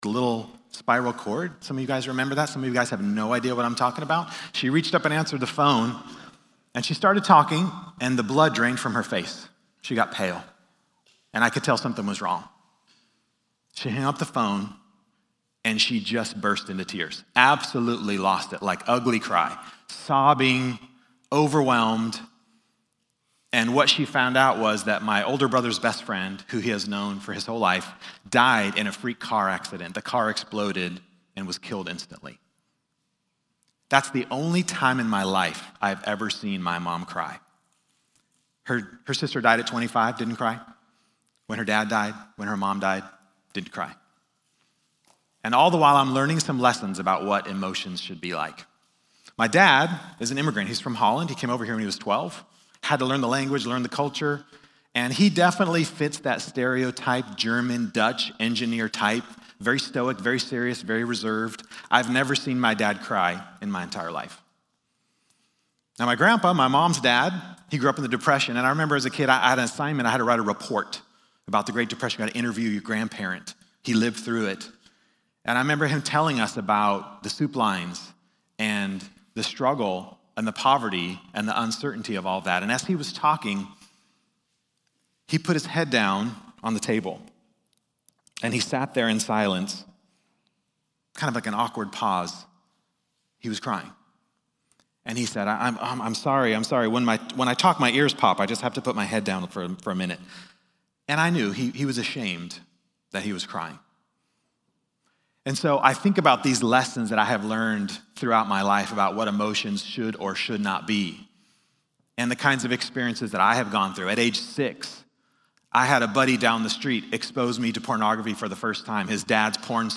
Men’s Breakfast